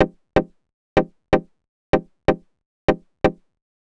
cch_synth_pluck_dry_125_Fm.wav